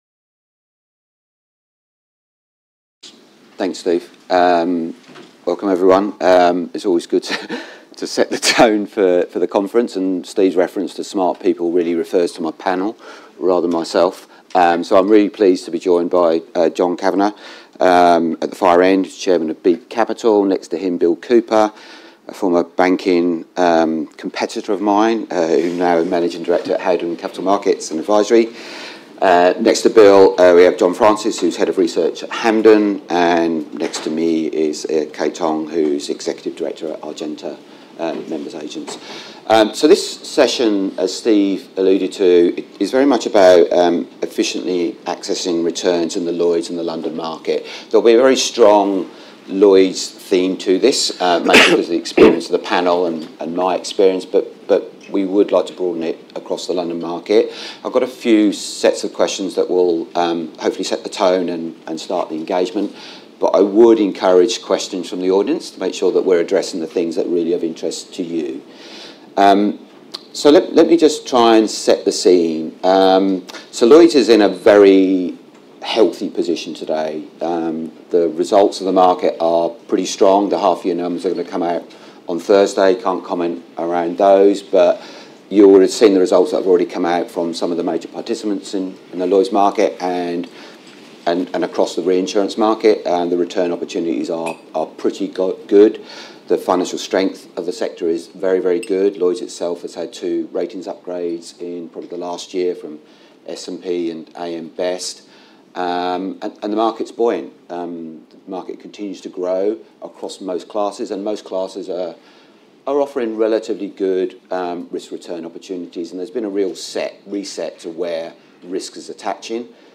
This episode features the first panel session of the day at our Artemis London 2024 conference, which was held on September 3rd, a discussion about allocating capital efficiently into the Lloyd's and London markets, to derive insurance-linked returns.